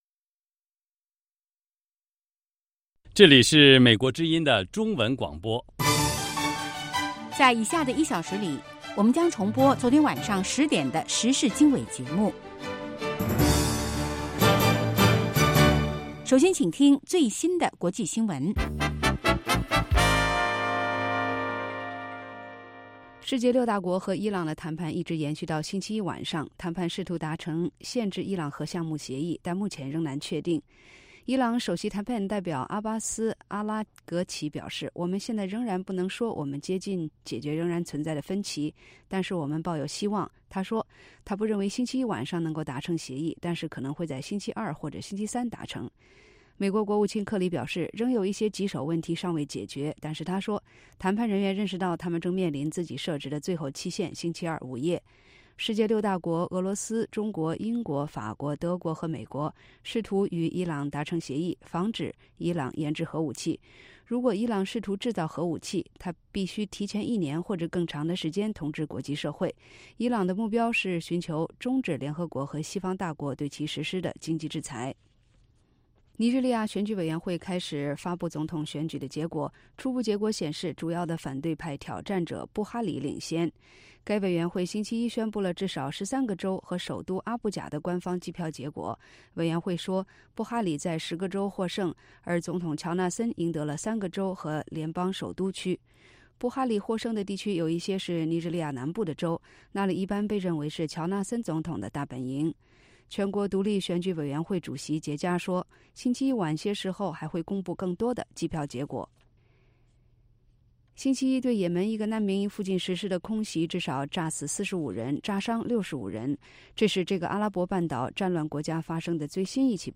北京时间早上8-9点广播节目 这个小时我们播报最新国际新闻，并重播前一天晚上10-11点的时事经纬节目。